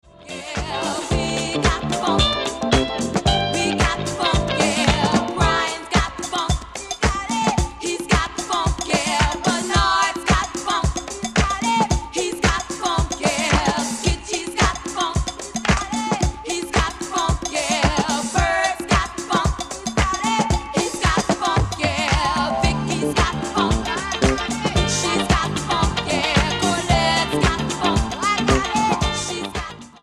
boogie anthem